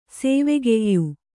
♪ sēvegeyyu